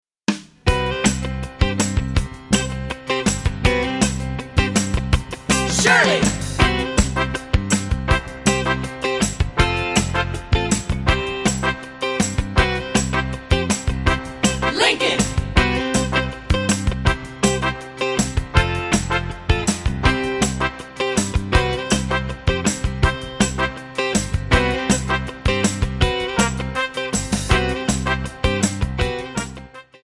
Backing track files: 1960s (842)
Buy With Backing Vocals.